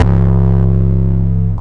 YN808_YC.wav